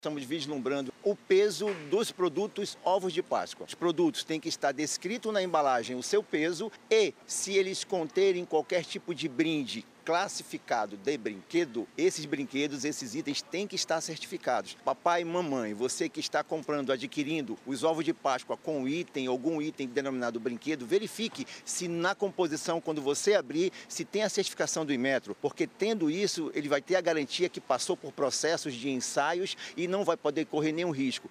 A ação aconteceu em um supermercado na zona sul de Manaus, onde foram verificados se os brindes contidos nos ovos de chocolate — caracterizados como brinquedos — possuem o selo do Instituto Nacional de Metrologia, Qualidade e Tecnologia (Inmetro) e a indicação da faixa etária adequada para a criança, como explica o diretor-presidente do Ipem-AM, Renato Marinho.